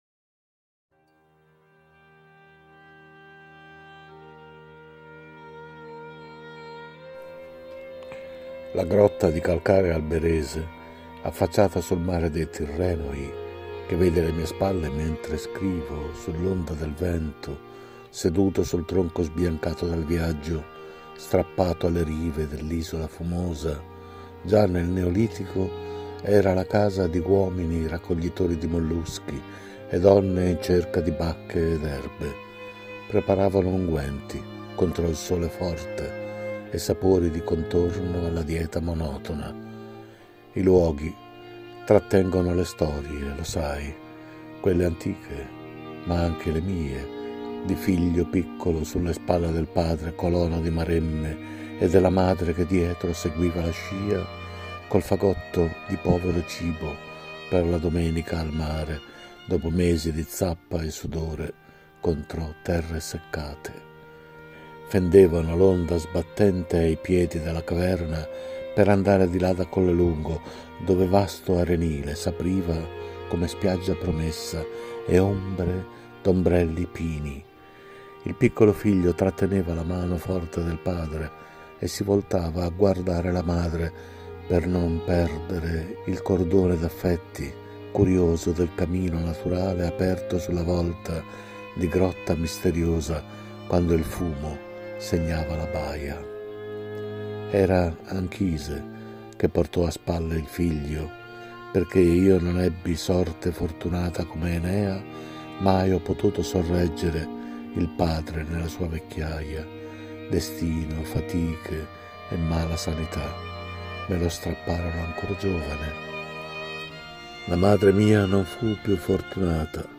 LA_GROTTA_DI_CALCARE_ALBERESEconmusica.mp3